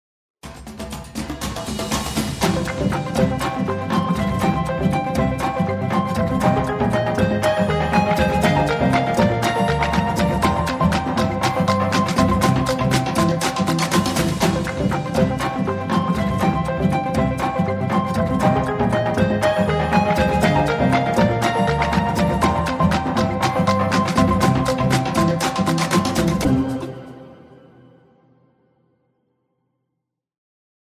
Synth rhythmic track for transitions & stingers.
Upbeat and positive tone track for transitions & stingers.